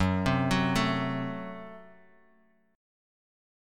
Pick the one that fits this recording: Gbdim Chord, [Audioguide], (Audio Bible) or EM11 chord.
Gbdim Chord